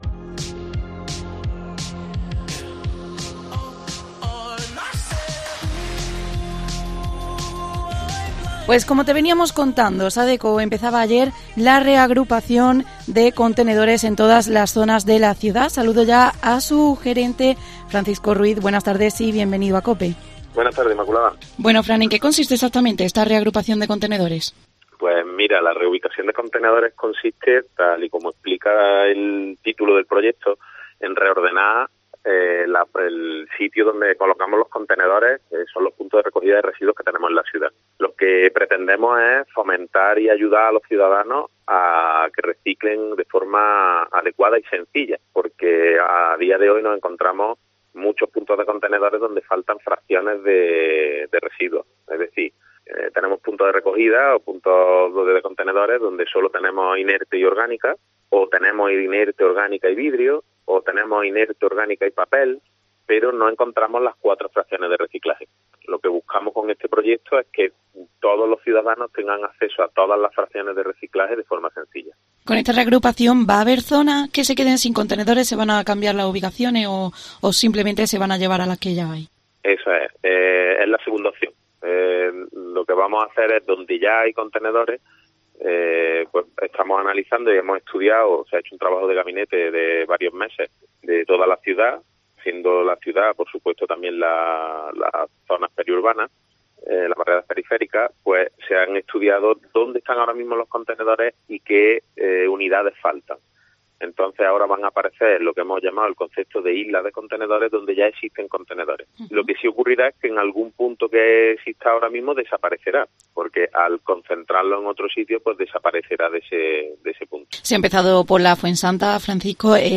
ha explicado en los micrófonos de COPE